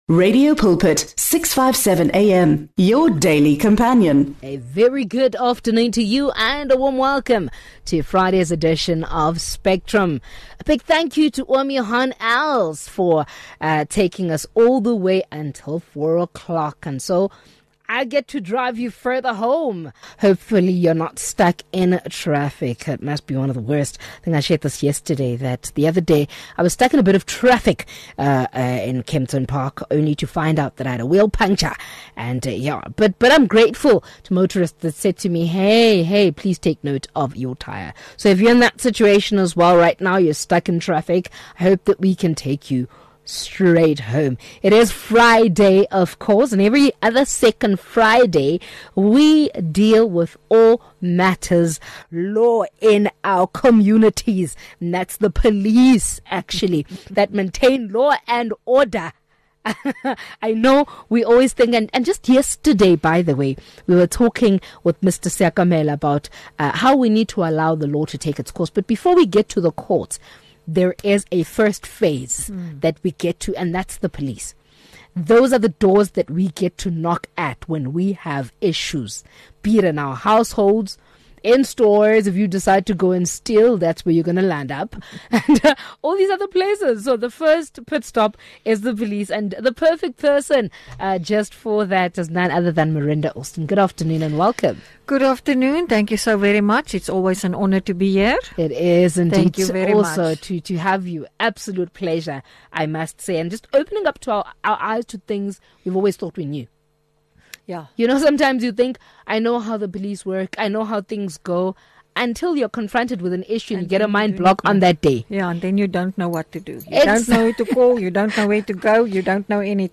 in-depth conversation